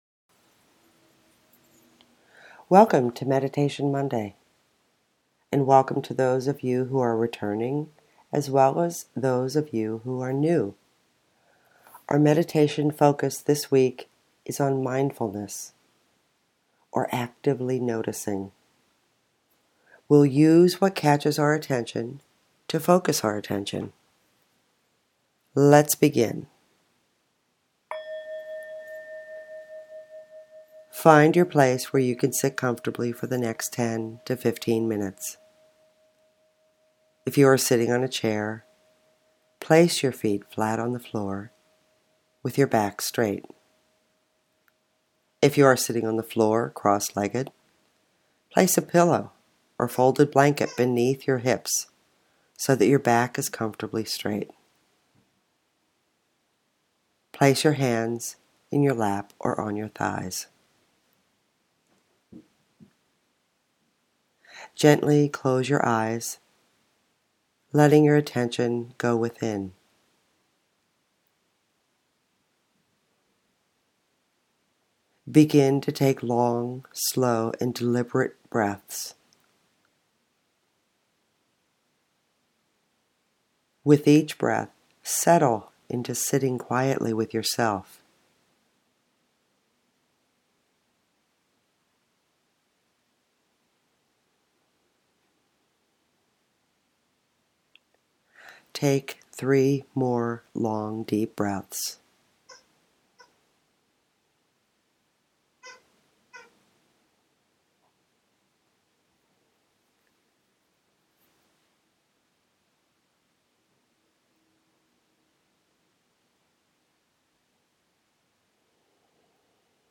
If mindfulness is new for you, consider listening to the guided meditation again and again throughout the week.